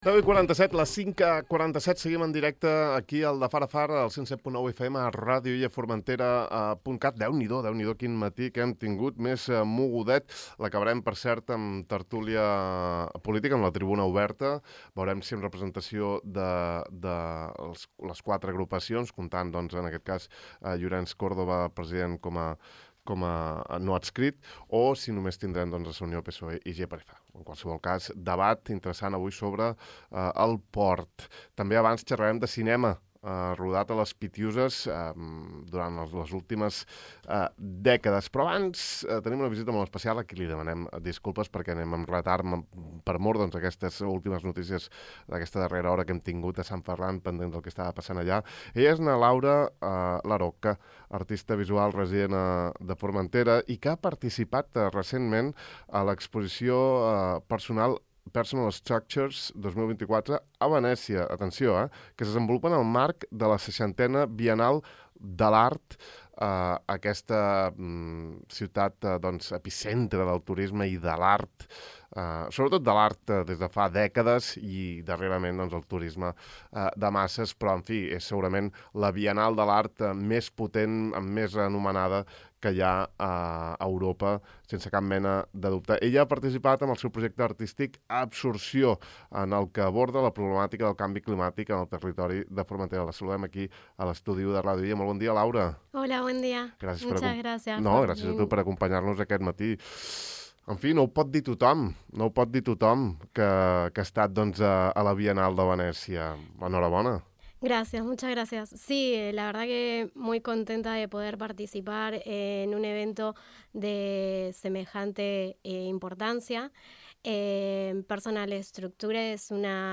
Avui hi hem xerrat, i ens ha contat una experiència única i la nova direcció que està emprenent en el seu projecte creatiu: